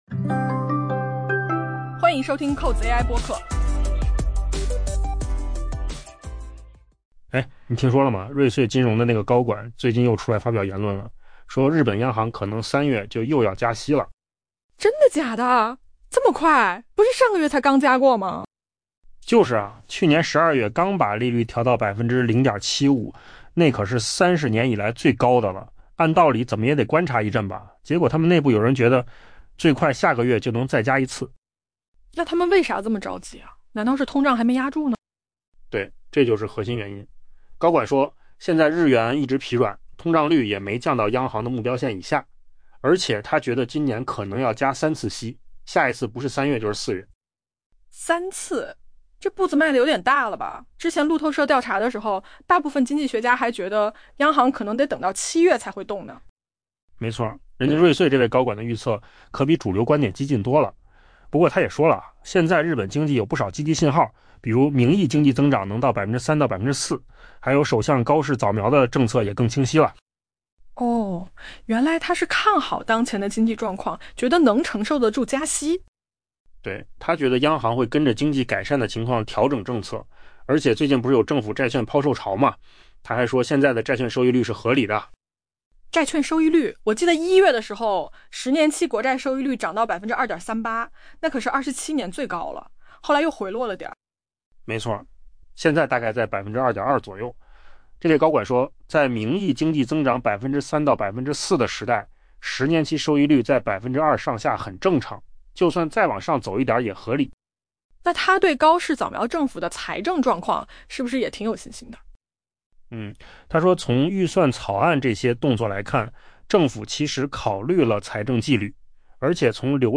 AI播客：换个方式听新闻
音频由扣子空间生成